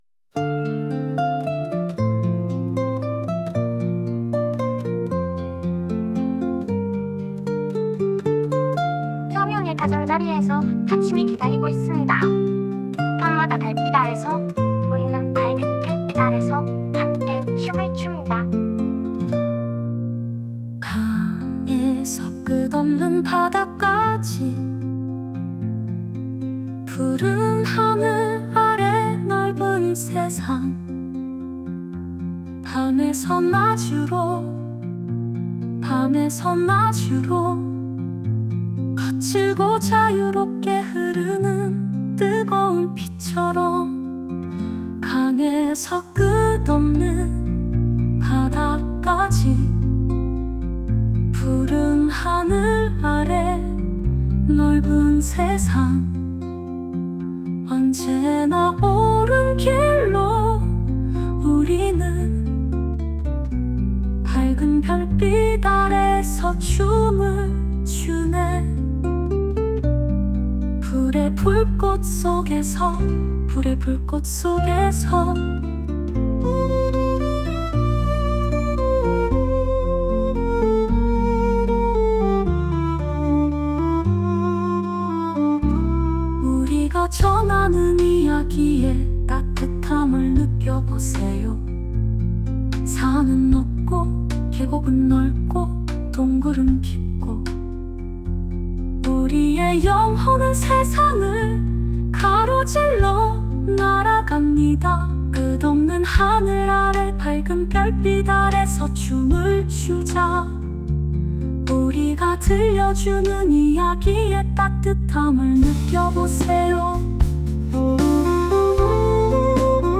Романс 2024.mp3
Одна задумана як романс у виконанні закоханої циганки, а українською як дійство, в стилі Руслани.